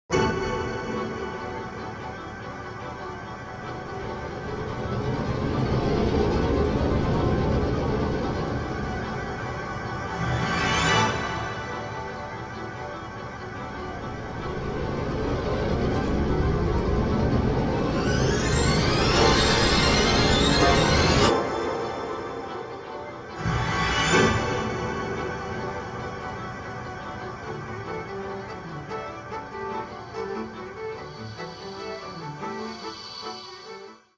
サンプル音源は全てステレオ(2ch)です。